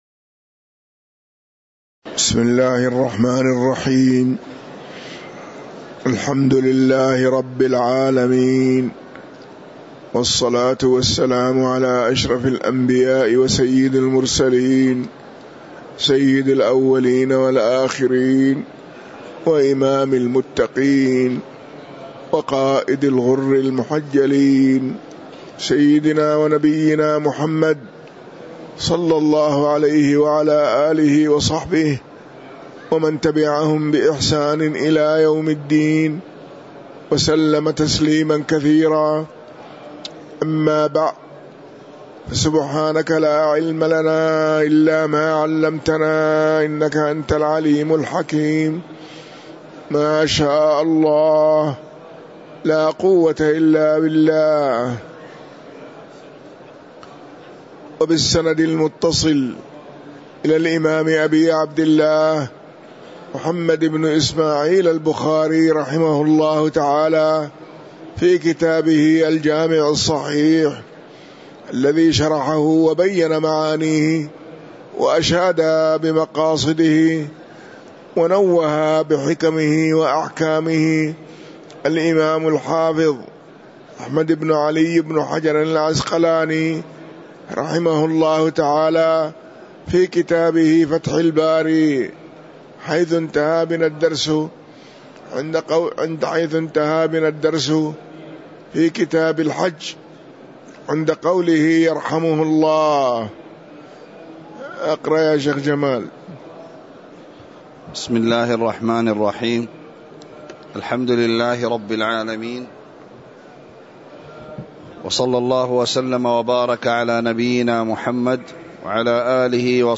تاريخ النشر ٢٠ جمادى الأولى ١٤٤٤ هـ المكان: المسجد النبوي الشيخ